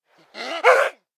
DayZ-Epoch/SQF/dayz_sfx/zombie/chase_5.ogg at 47212dd69145b63cfc31304a59f6336a153114e8